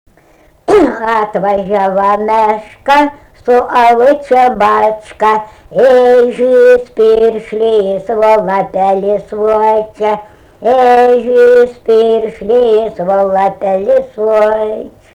daina, vaikų
Barvydžiai
vokalinis